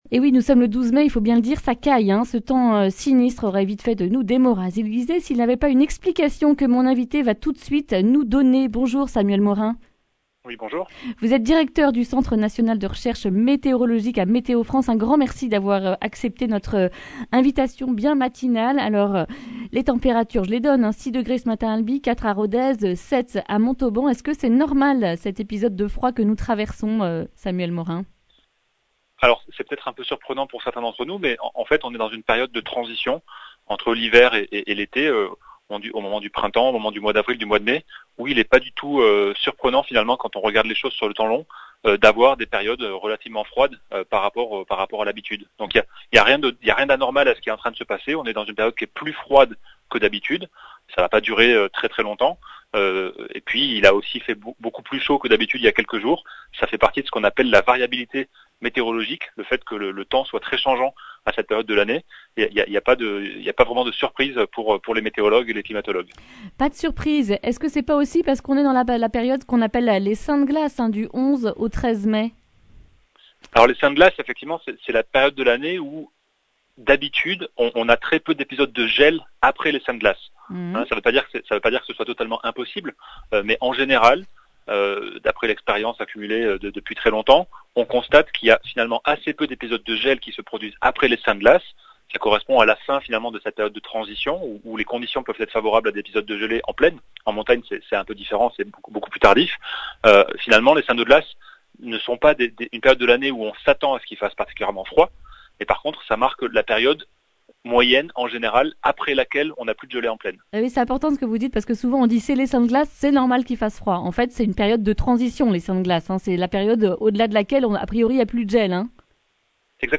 mercredi 12 mai 2021 Le grand entretien Durée 11 min